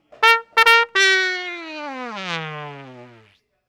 trumpet.wav